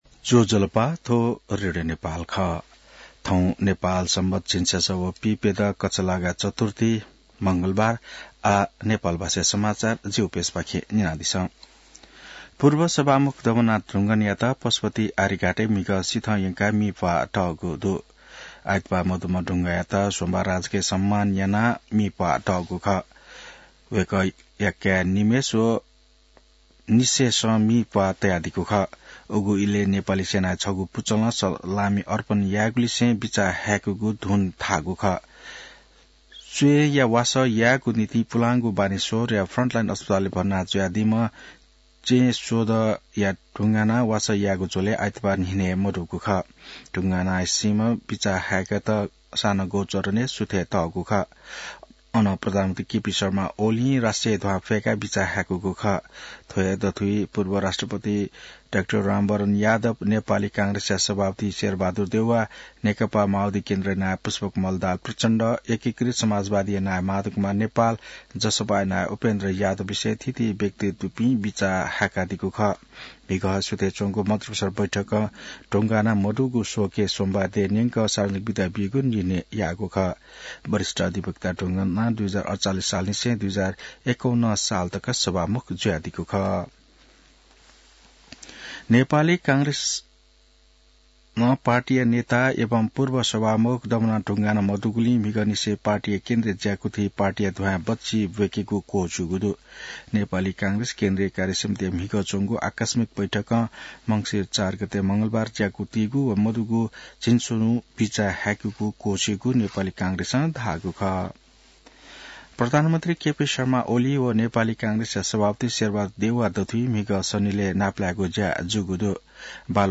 नेपाल भाषामा समाचार : ५ मंसिर , २०८१